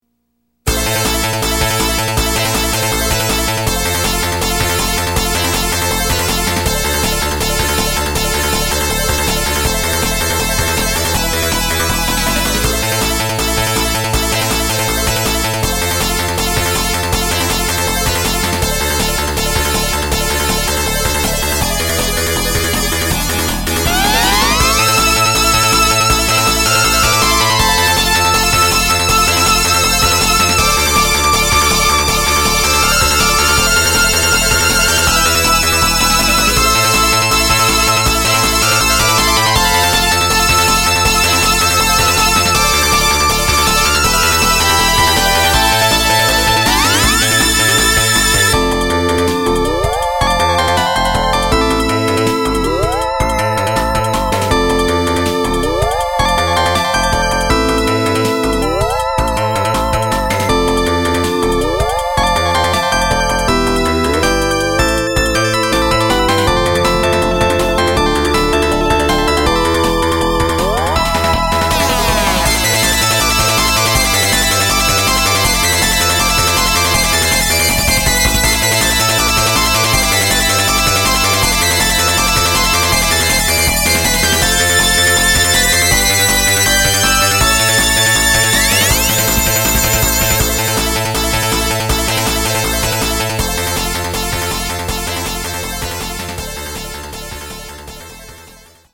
サークルで学園祭展示用に作成したシューティングゲームのＢＧＭです。 このために86音源を買ってきました。